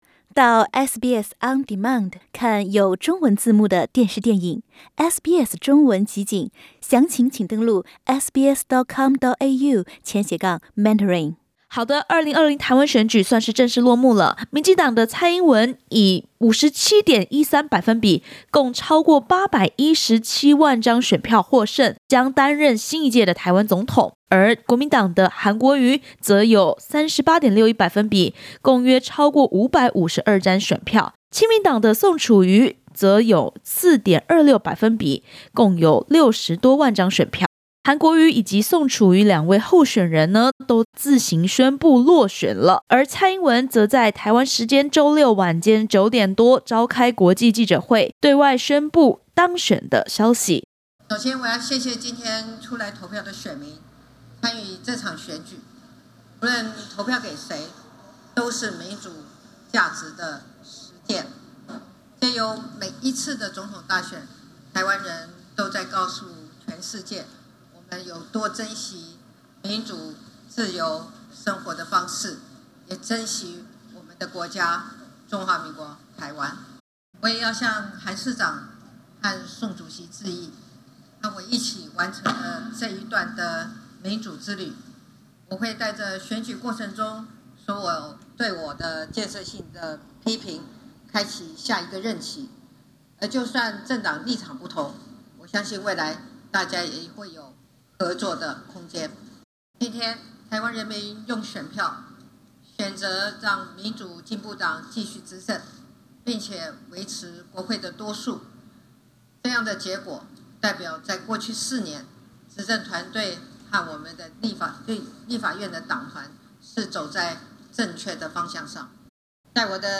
蔡英文总统在发表胜选演说时表示，选举结果反映台湾民众追求民主和自由的决心，也强调，对于国际社会而言，台湾是大家的伙伴、不是议题。